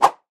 Звуки для подписки
Звук ветра